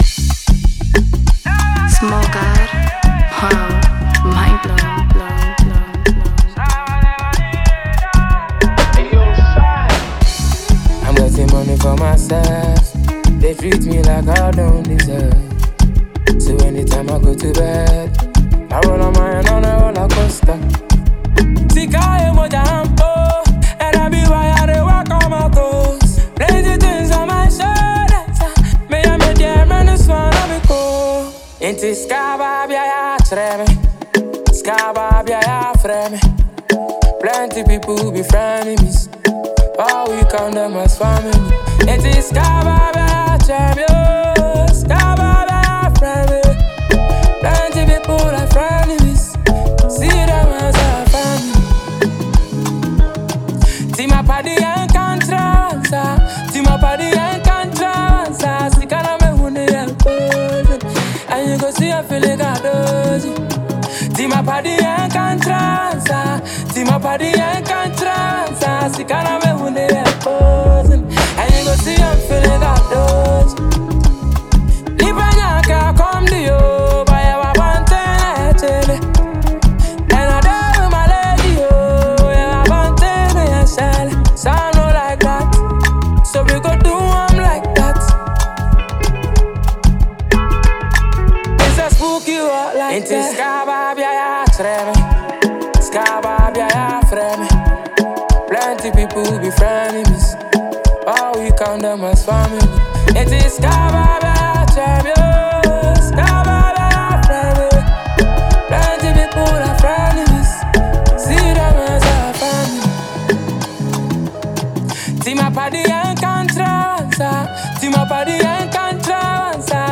highlife and Afrobeats